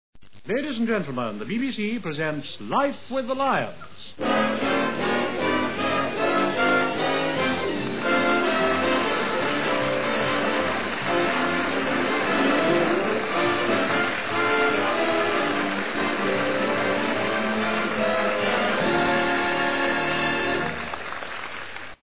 Intro and Signature Tune